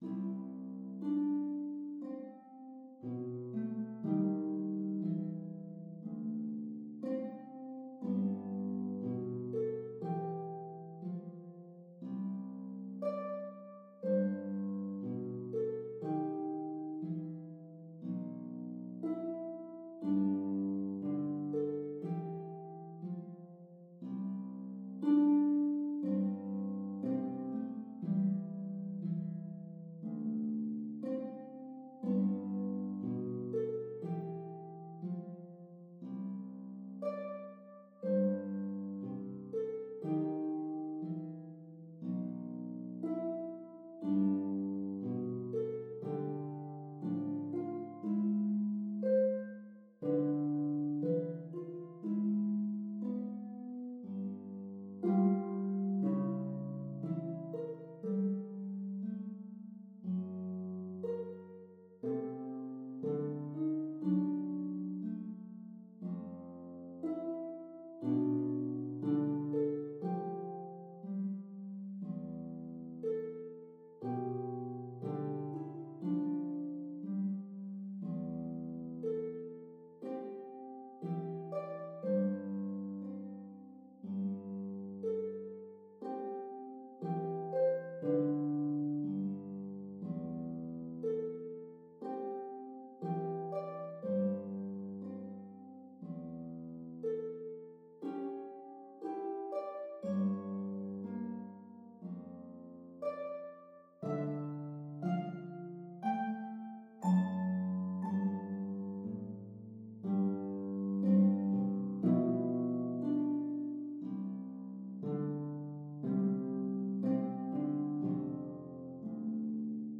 Transcribed for 14-string/course d-minor theorbo.